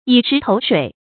以石投水 yǐ shí tóu shuǐ 成语解释 象石头投入水里就沉没。